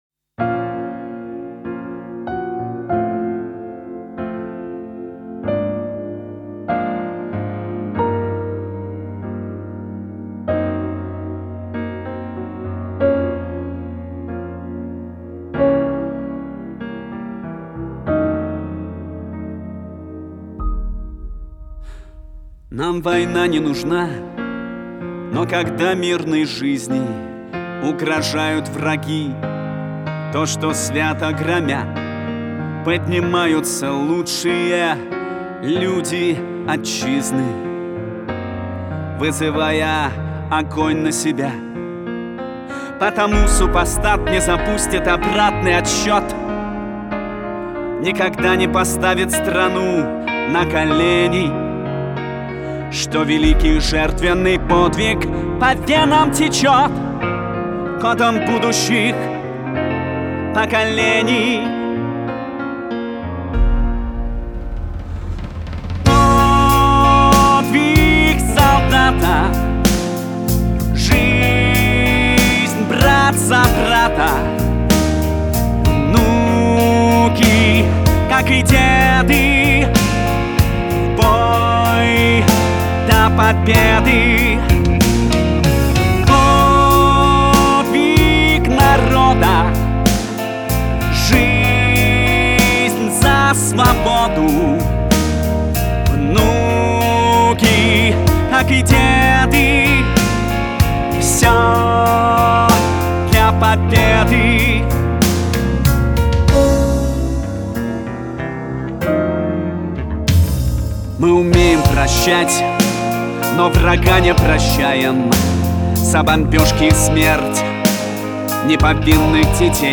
Композиция в стиле «рок» очень пронзительна и эмоциональна.